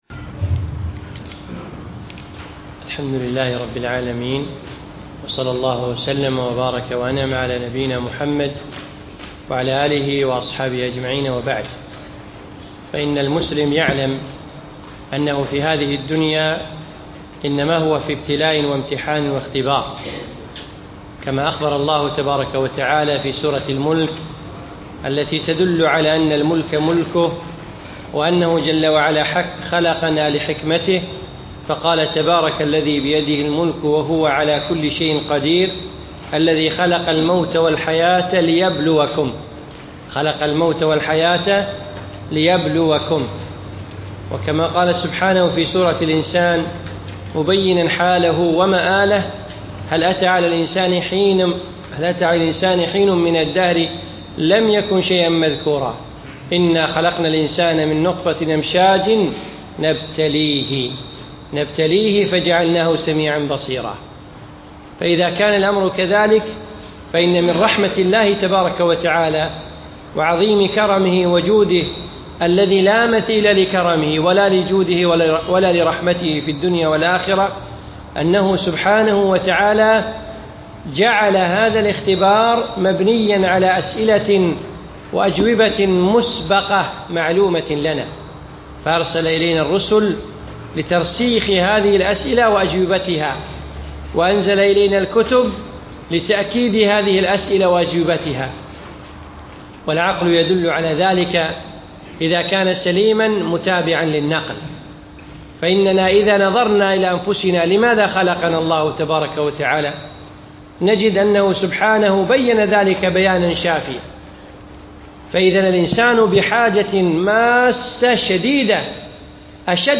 أقيمت الدورة في مسجد عبدالله الحمادي بمنطقة المهبولة من 6 1 إلى 8 1 2015
الدرس الأول